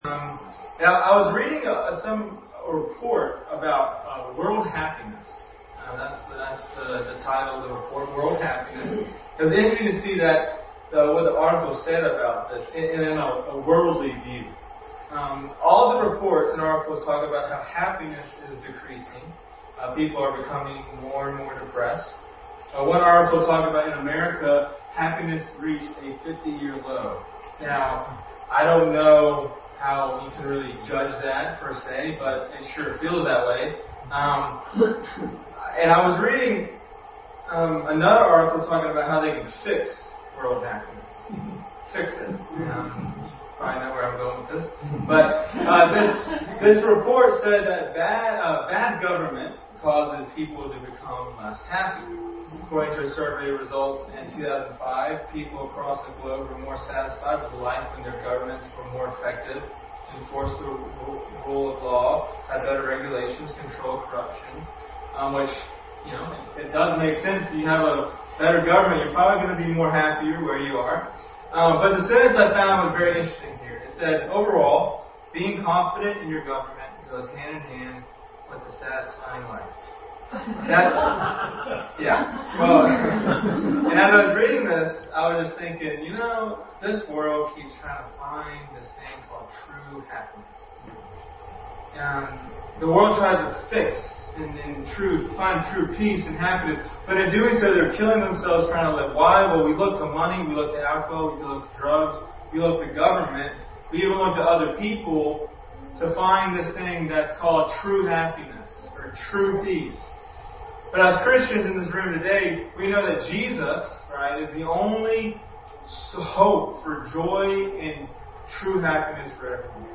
True Happiness Passage: Psalm 146, 28:7, 63:7, Jeremiah 17:7, 9, 2:13, Isaiah 41:10 Service Type: Family Bible Hour